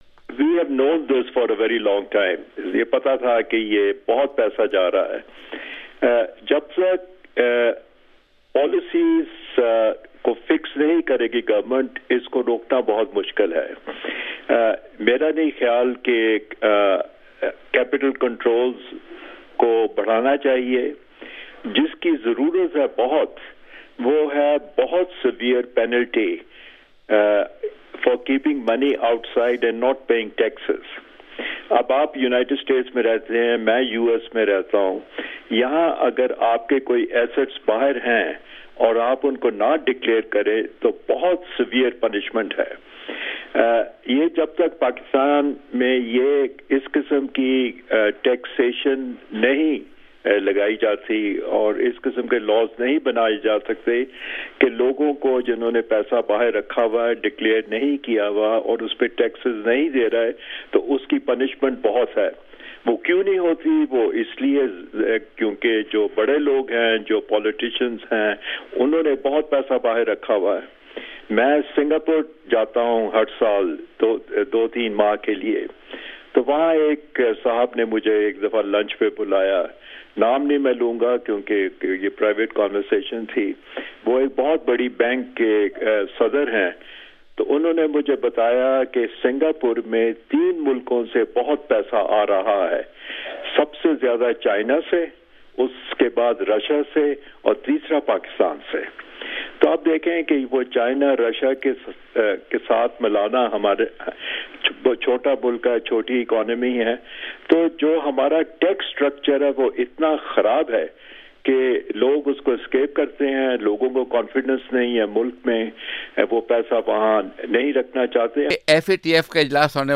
پروگرام 'جہاں رنگ' میں گفتگو کرتے ہوئے ممتاز ماہر معاشیات شاہد جاوید برکی نے کہا کہ حکومت کو اس سلسلے کو روکنے کے لئے سخت پالیسیاں بنانی ہوں گی اور ان لوگوں پر سخت جرمانے کرنے ہونگے جو اپنی رقوم باہر کے بنکوں میں رکھتے ہیں اور اس پر ٹیکس ادا نہیں کرتے